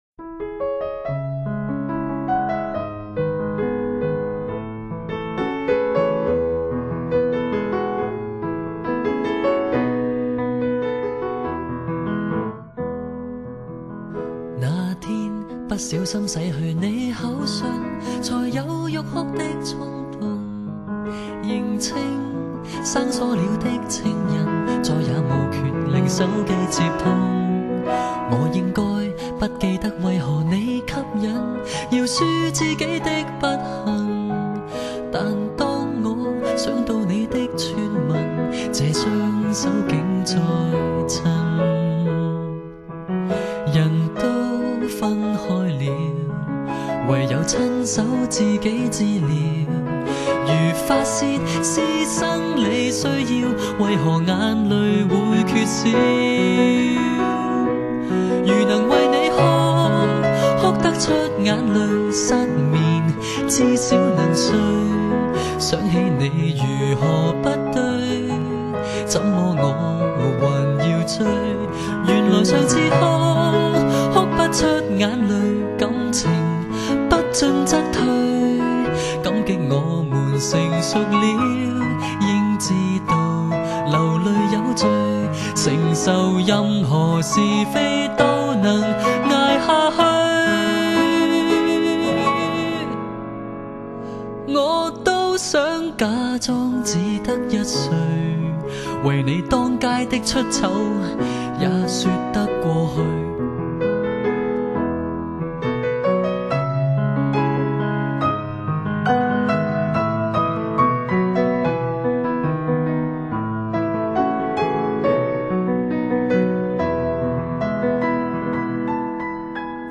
乐器永远是Guitar和Piano为主流